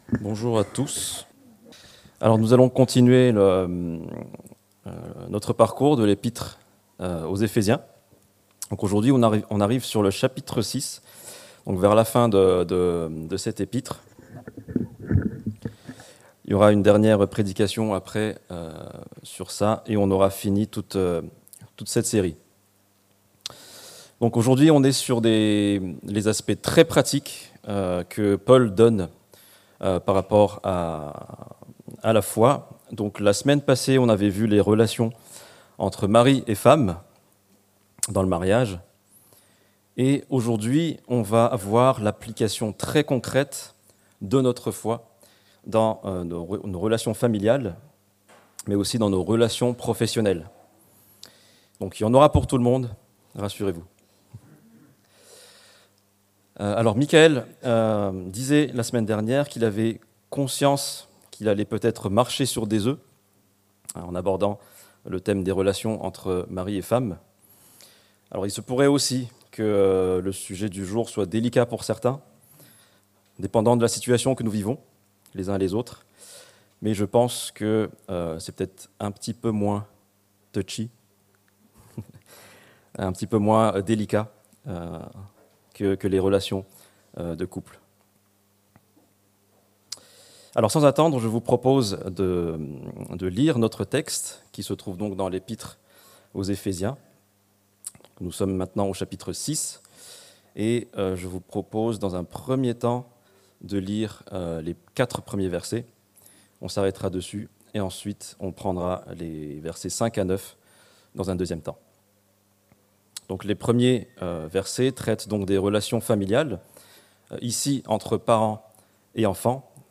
Notre foi dans nos relations familiales et professionnelles - Prédication de l'Eglise Protestante Evangélique de Crest